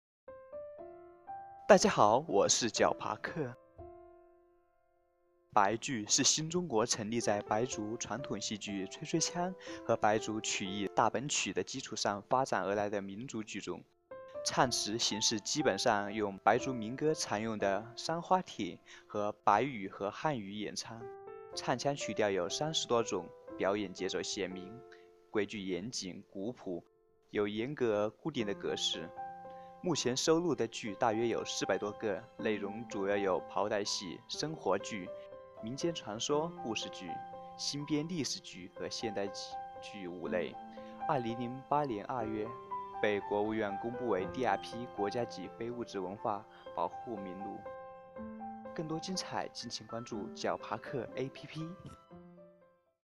白剧是新中国成立后在白族传统戏剧“吹吹腔”和白族传统曲艺“大本曲”的基础上发展起来的民族剧种。唱词形式基本上用白族民歌常用的“山花体”，用白语和汉语演唱，唱腔曲调有三十多种，表演节奏鲜明，规律严谨、古朴，有严格而固定的程式。目前收集到的剧目大约400多个，内容主要有袍带戏、生活剧、民间传说故事剧、新编历史剧和现代戏等五类。